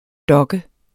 Udtale [ ˈdʌgə ]